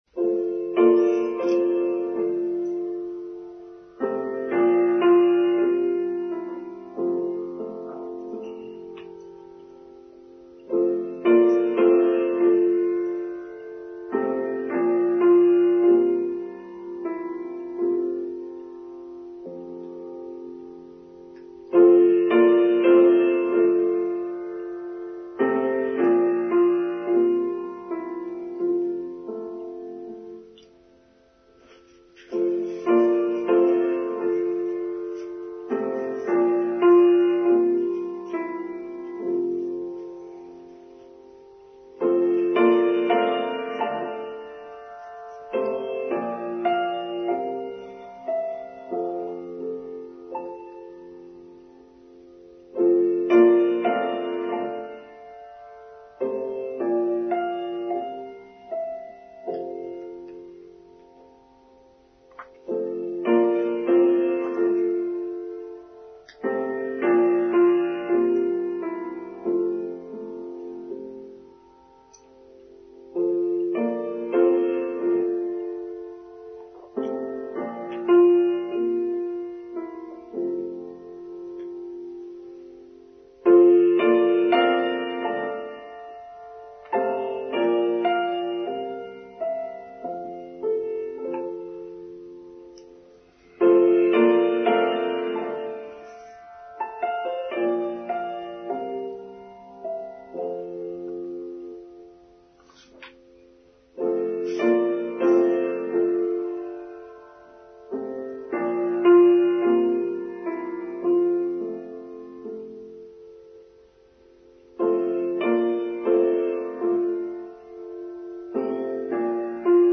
The Wisdom and Sacredness of Trees: Online service for 6th August 2023